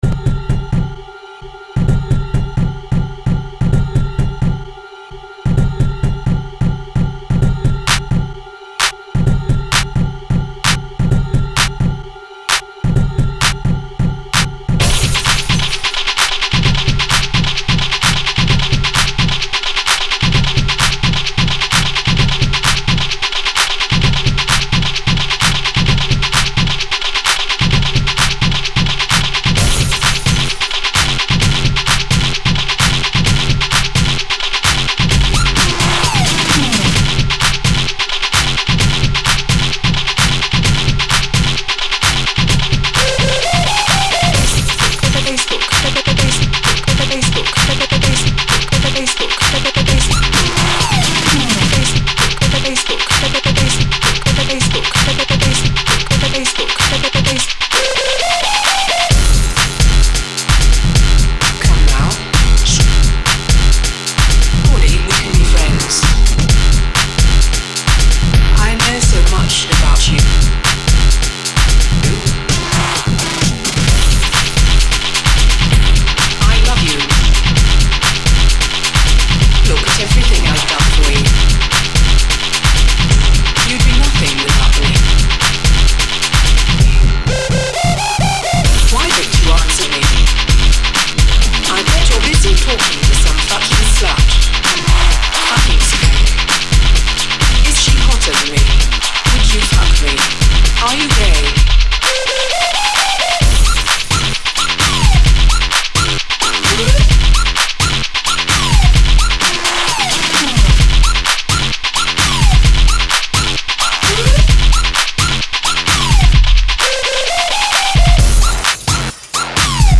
Ghetto Bass music qui remue le Booty